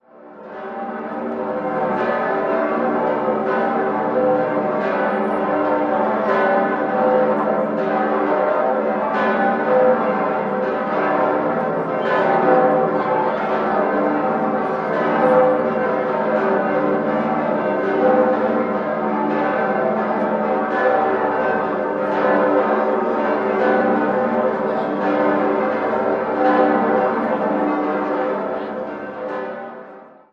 11-stimmiges Geläute: g°-c'-es'-e'-g'-g'-a'-b'-c''-d''-e''
Erweitertes Domgeläut seit 24. November 2007
Unverwechselbares Großgeläute mit ganz eigenwilligem Reiz.
Die acht Renaissanceglocken bilden das größte geschlossen erhaltene Renaissancegeläute der Welt.
Freising_Dom.mp3